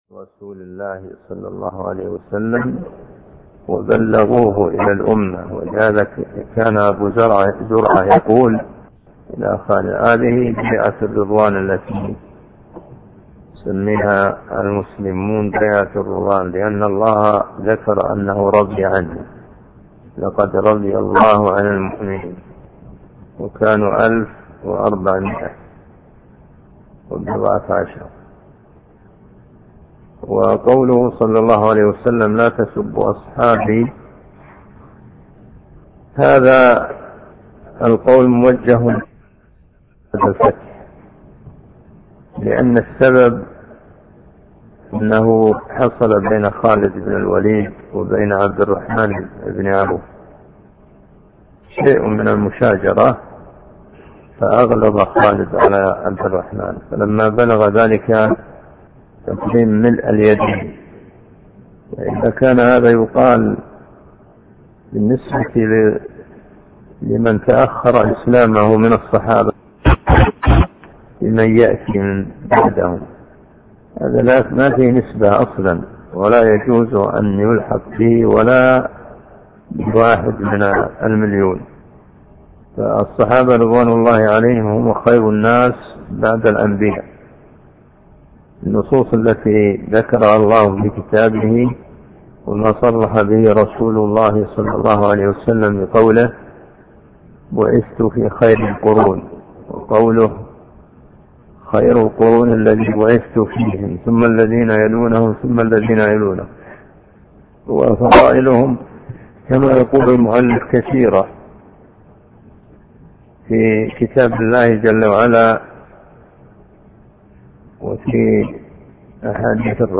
عنوان المادة الدرس (7) شرح رسالة ابن تيمية الى عدي بن مسافر